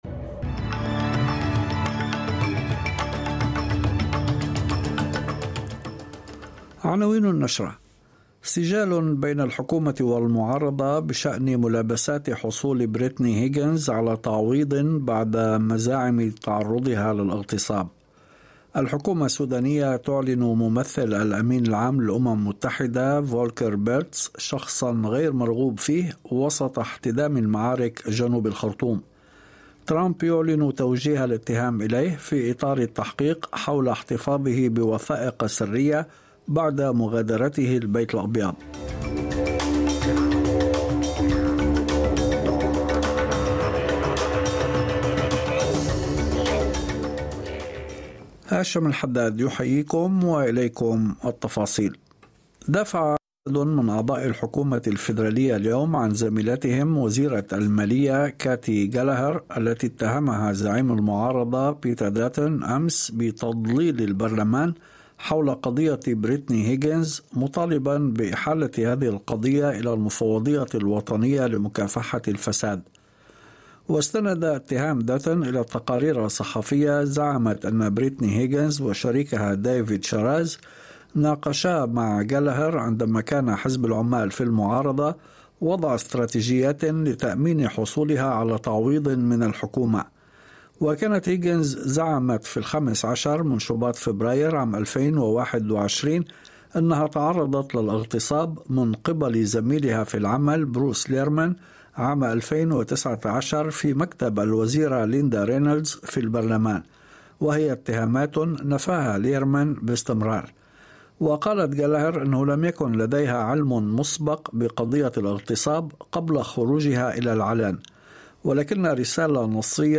نشرة أخبار المساء 09/06/2023